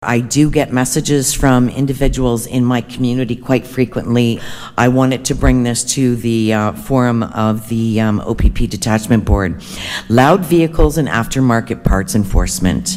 That was Chair Kim Huffman, who also serves as the community’s councillor.